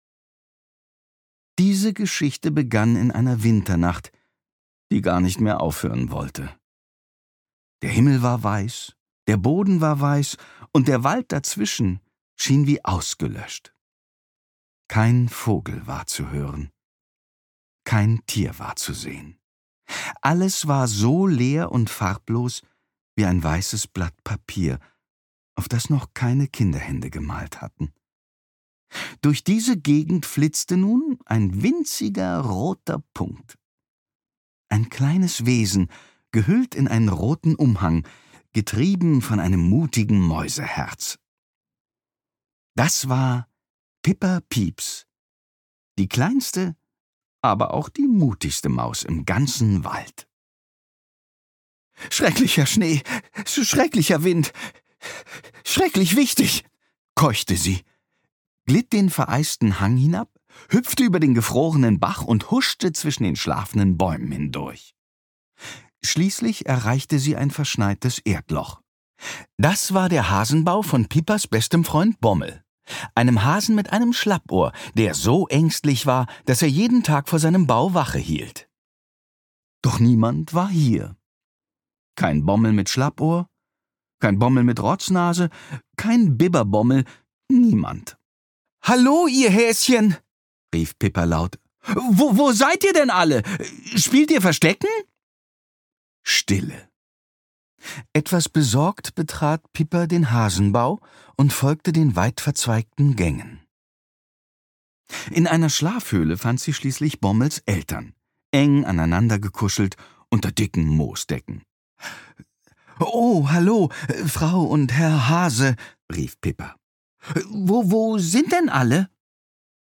Versiert tupft er stimmlich die Farben in die Geschichte und macht sie so unterhaltsam bunt wie ein Hörspiel.